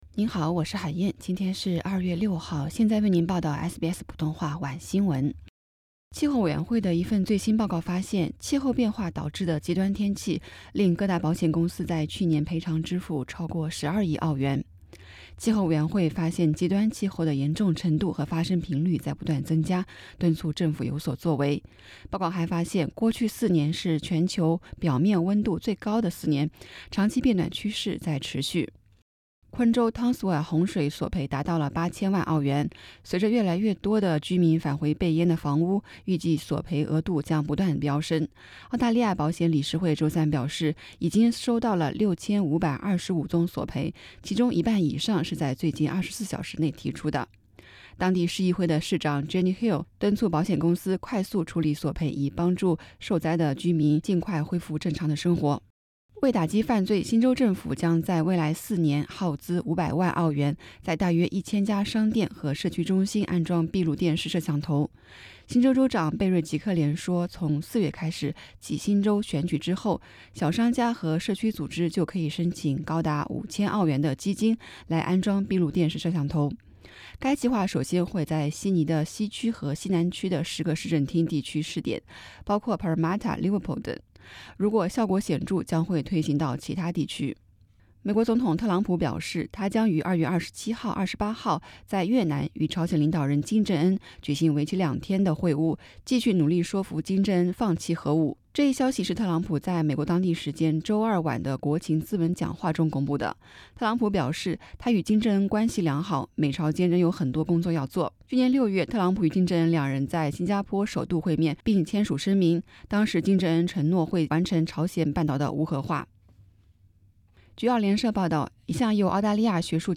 SBS 晚新闻 （2月6日）